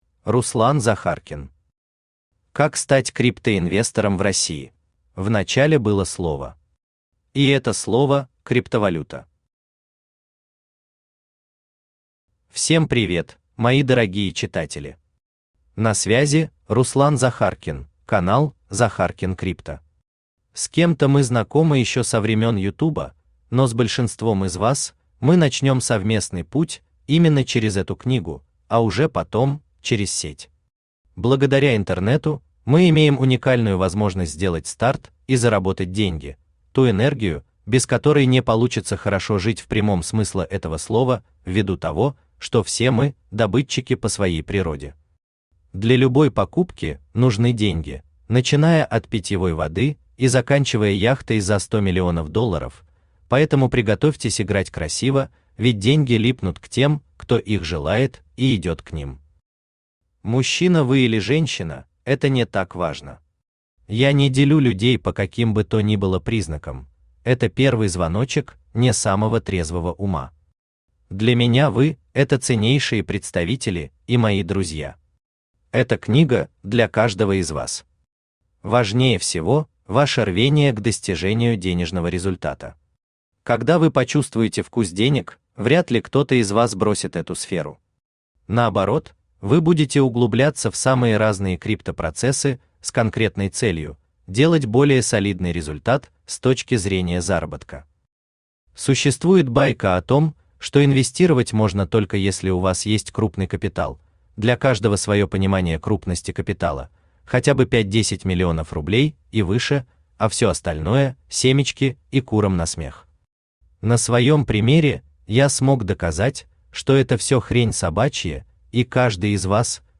Аудиокнига Как стать криптоинвестором в России | Библиотека аудиокниг
Aудиокнига Как стать криптоинвестором в России Автор Руслан Игоревич Захаркин Читает аудиокнигу Авточтец ЛитРес.